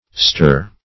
-ster \-ster\ [OE. & AS. -estre, -istre.]